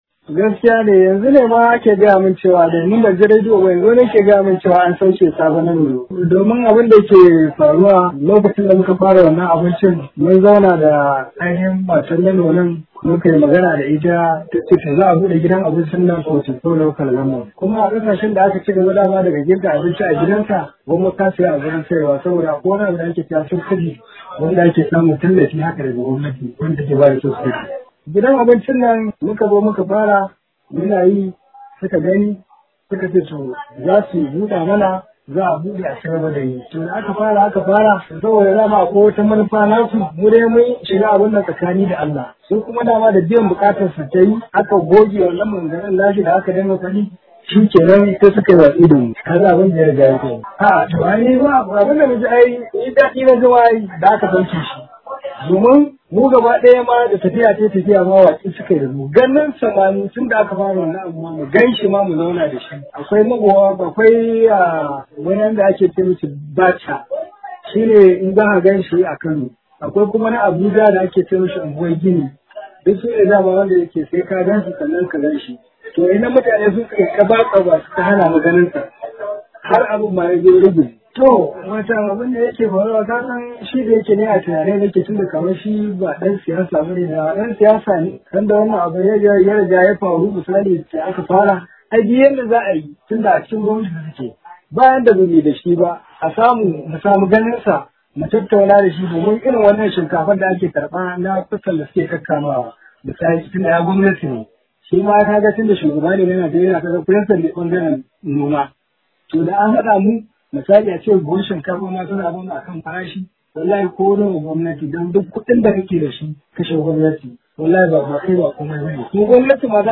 A wata tattaunawa da Dala FM ta yi